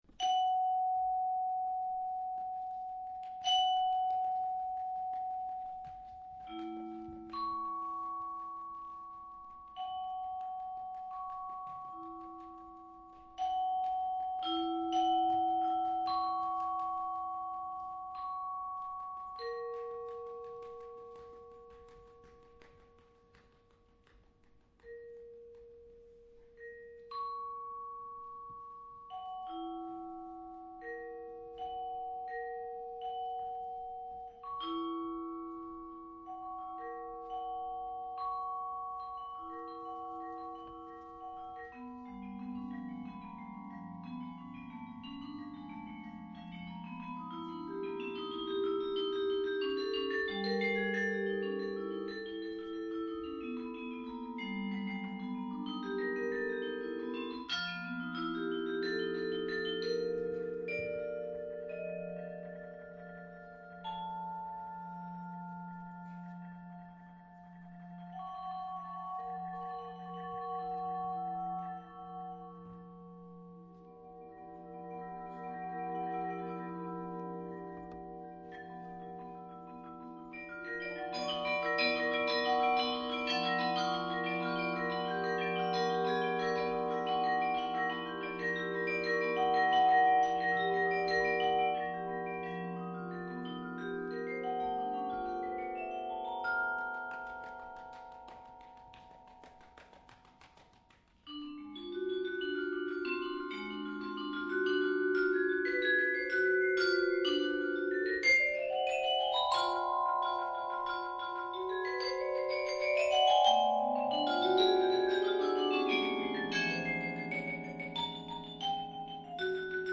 -tr�o de percusi�n-